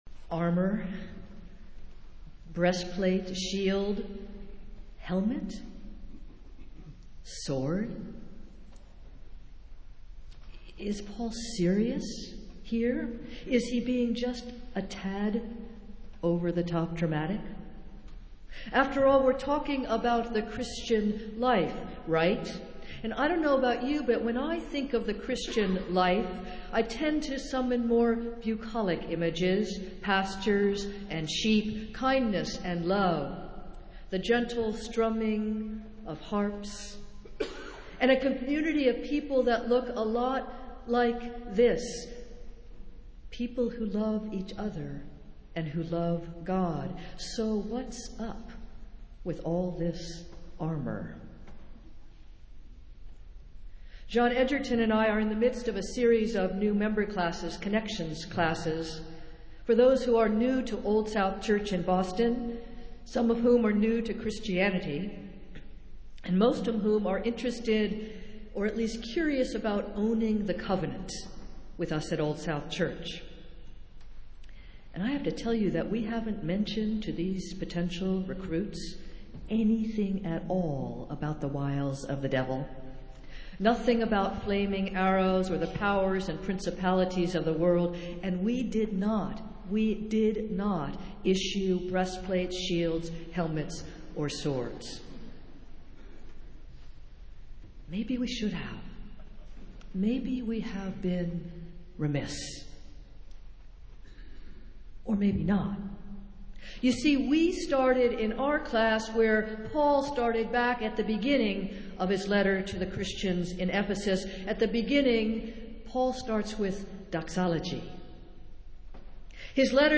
Festival Worship - Celebrate the Mission Sunday